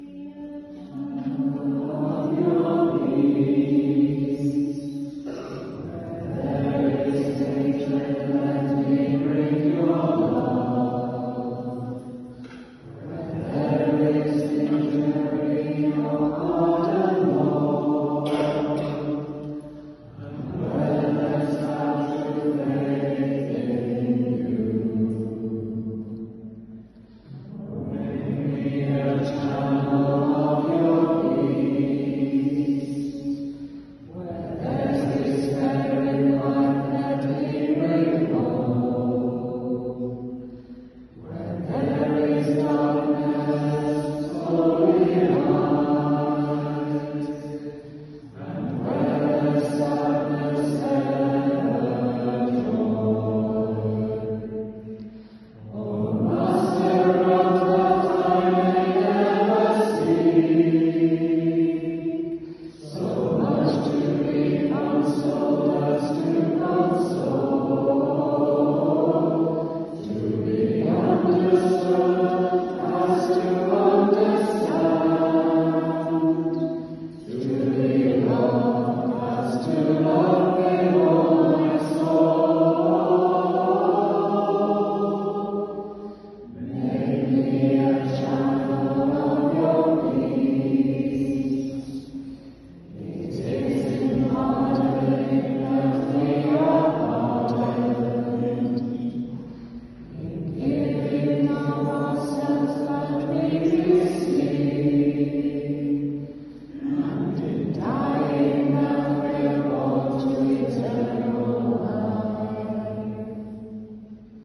Today we began with Mass at the Basilica of St Clare, in the chapel of St Agnes (t0 the left of the main altar):
To conclude our Mass we sang the hymn that is wrongly attributed to St Francis, but still very much echoes his spirituality. It is a hymn which dates from the time of the First World War, teaching that peace begins with each one of us: